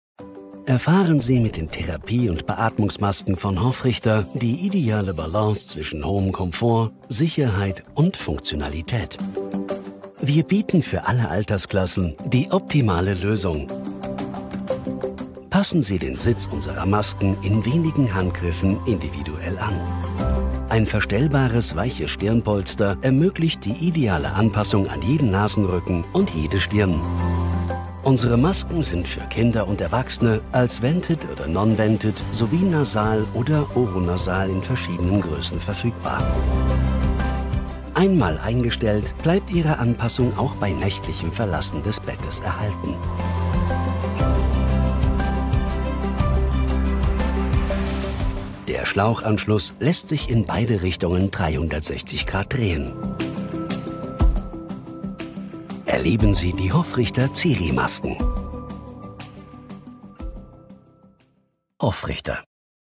markant, sonore Stimme, kernig Stimmalter: 35-65
Sprechprobe: Industrie (Muttersprache):
If you are looking for a distinctive, sonorous voice, then you have come to the right place.